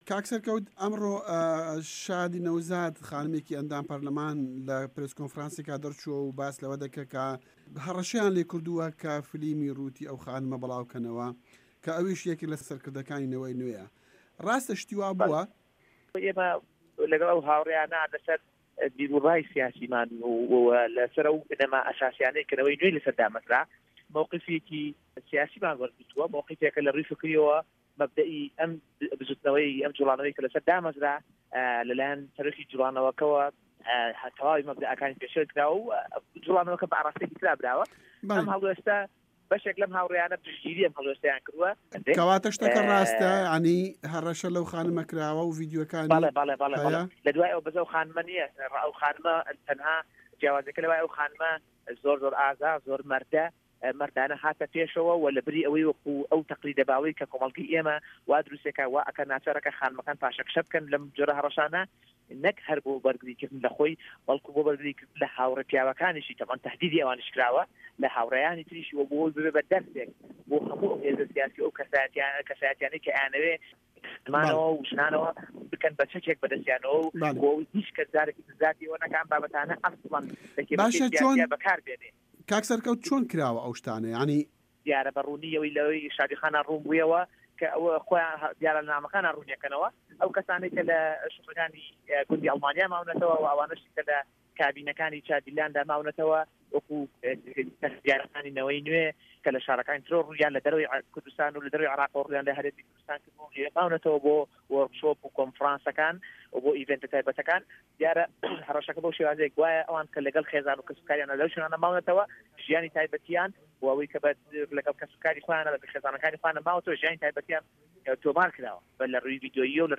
وتووێژ لەگەڵ سەرکەوت شەمسەدین